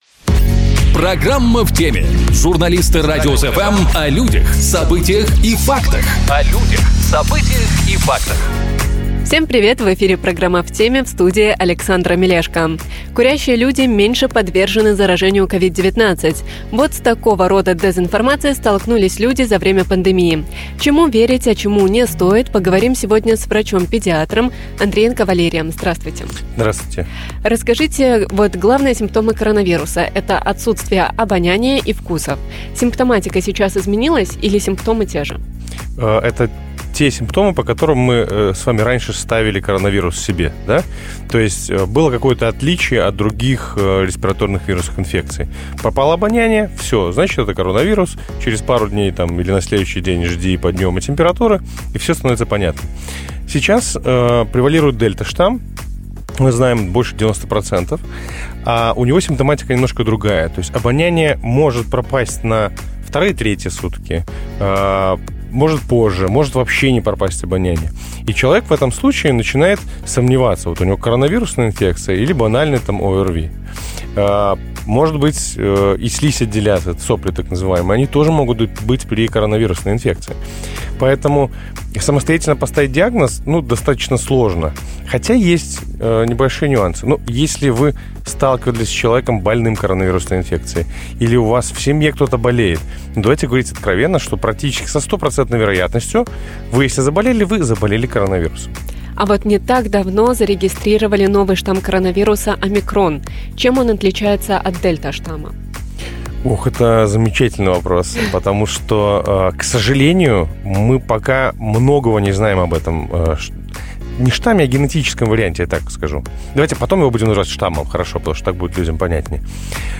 врач педиатр